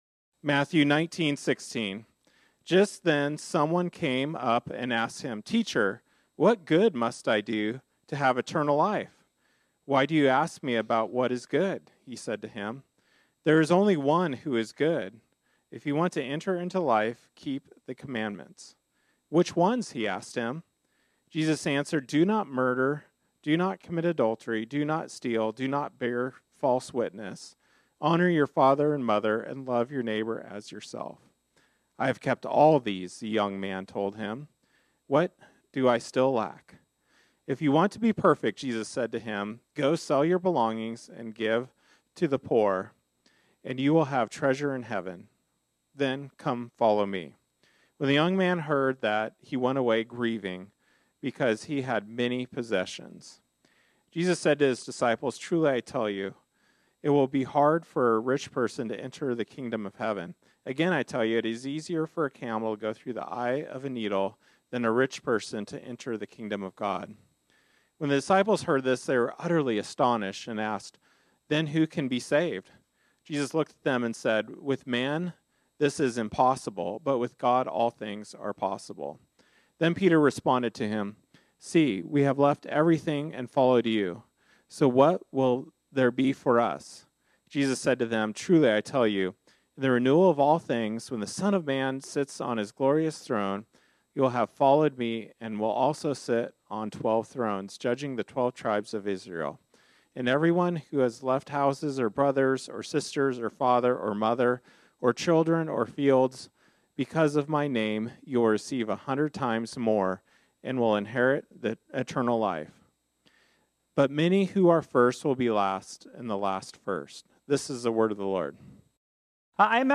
This sermon was originally preached on Sunday, September 8, 2024.